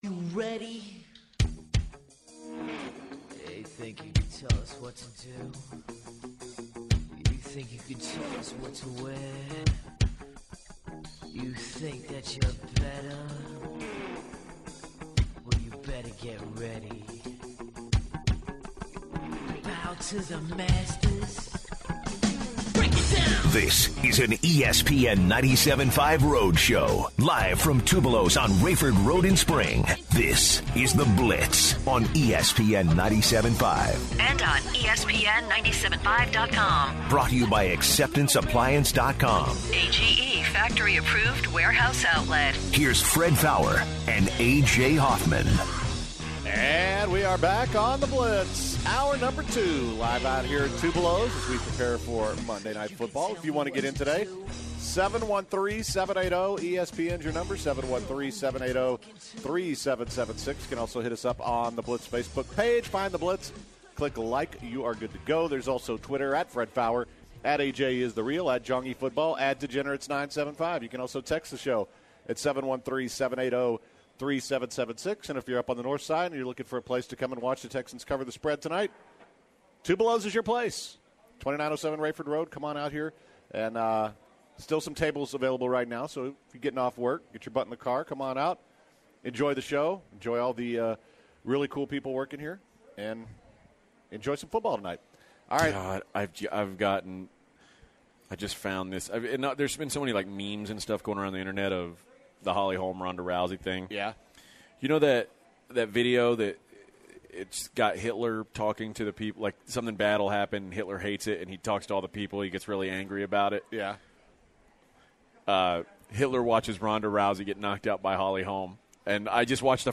The Guys talk to Former NFL and UH cornerback Stanford Routt about Sundays NFL games.